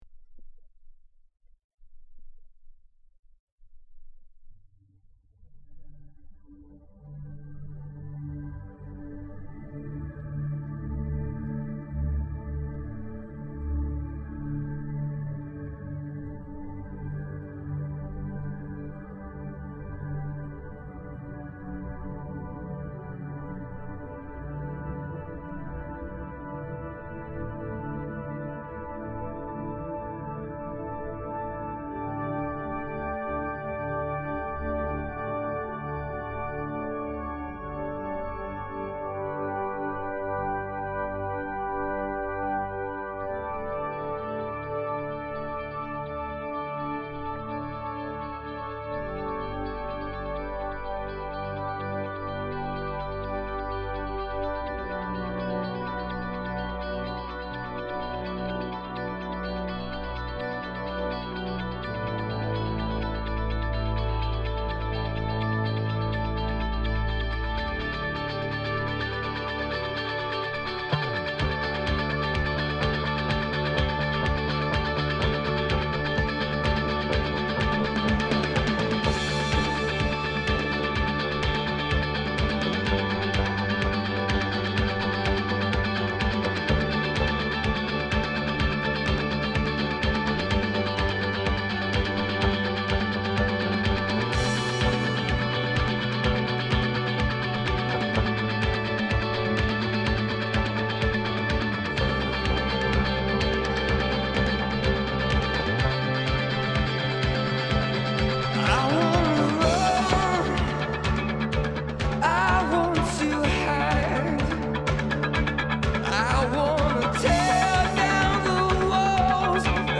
mp3 file that plays as static unless you seek in to it